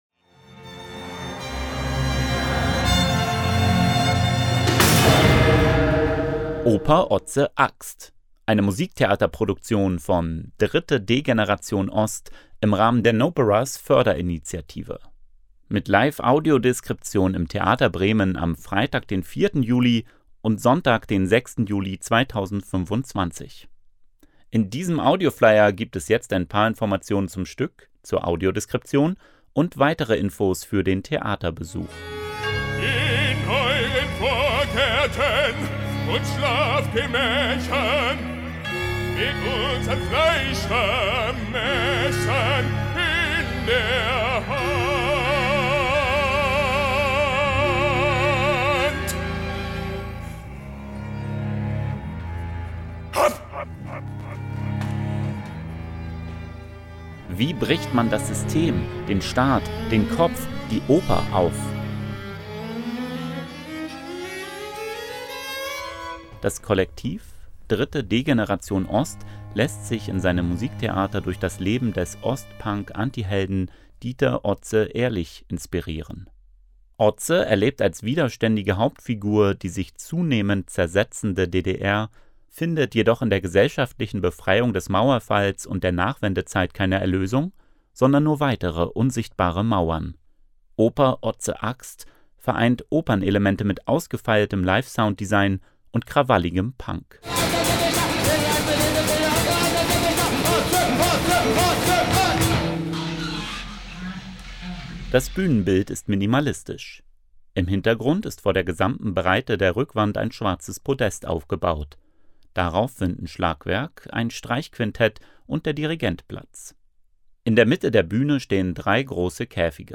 Oper Otze Axt ist ein musikalisches Experiment mit melodiösen Opernelementen, krawalligem Punk, ausgefeiltem Live-Sounddesign, fünf Streichern, Schlagwerk und Solist:innen.
Musikalische Ausschnitte: Staatstheater Darmstadt und Musiktheater im Revier Gelsenkirchen